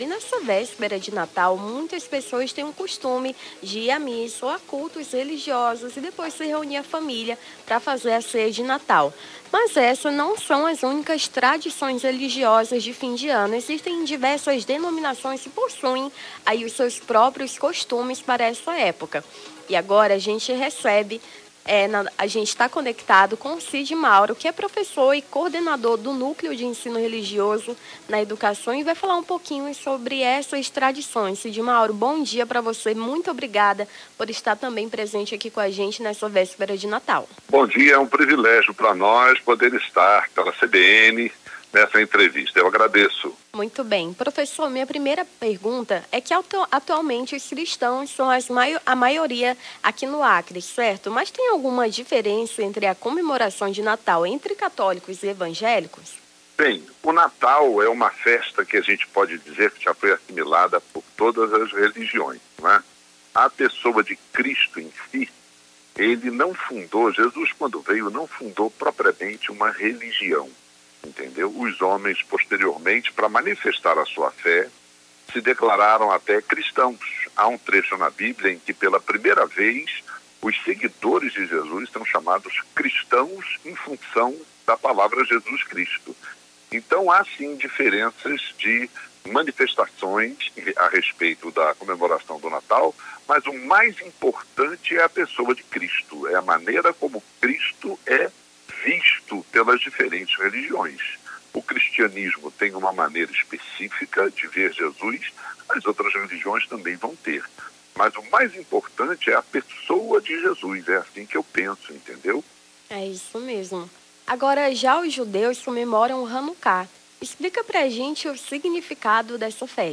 Nome do Artista - CENSURA - ENTREVISTA TRADIÇÕES RELIGIOSAS (24-12-24).mp3